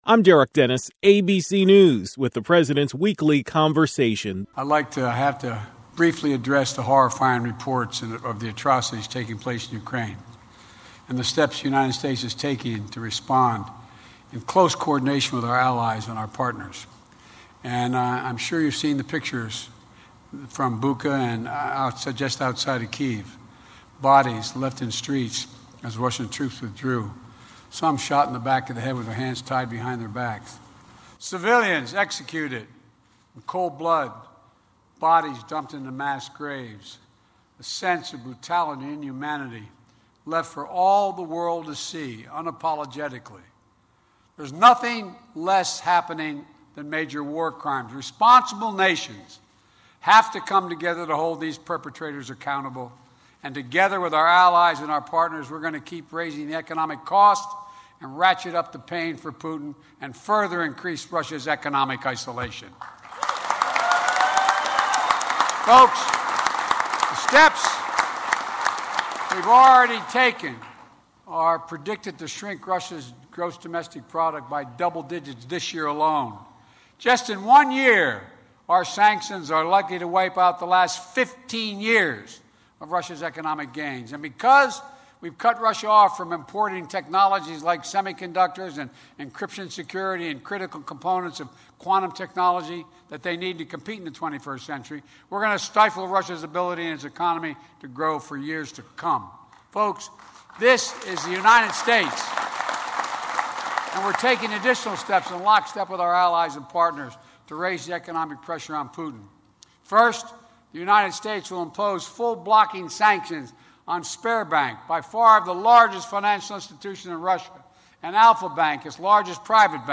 President Biden delivered remarks on the latest steps the U.S.A. is taking to put pressure on Russia.